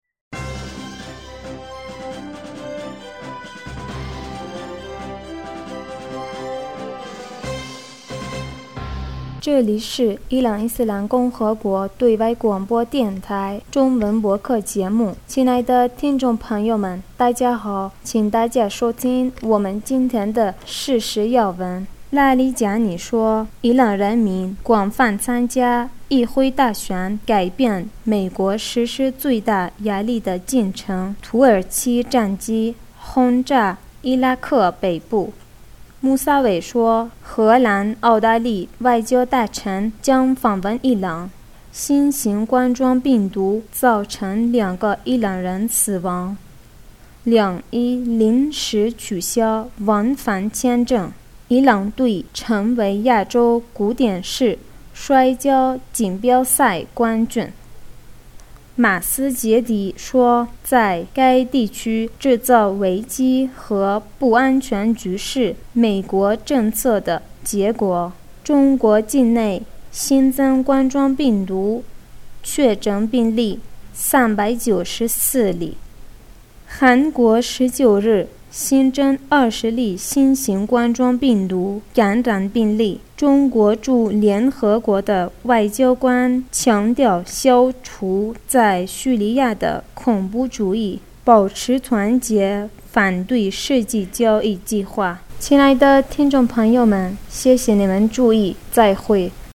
2020 年 2月20日 新闻